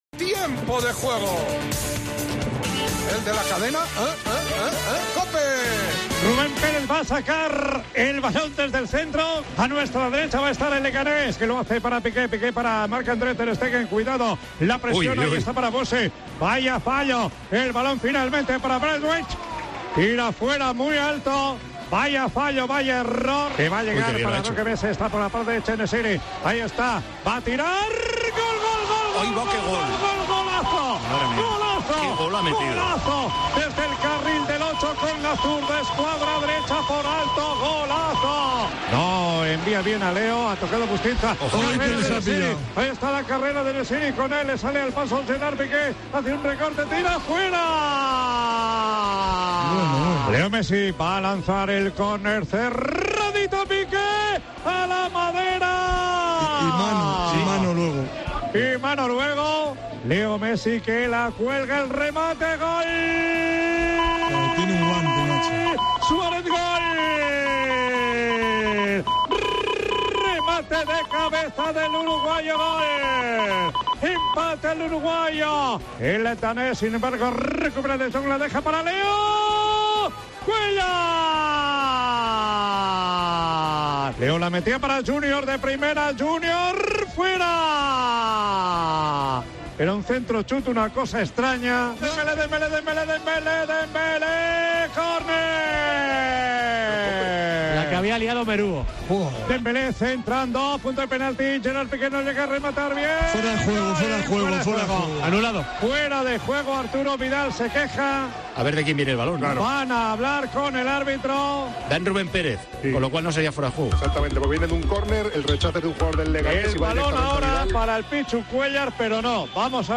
Així van sonar els gols del Barça a Leganés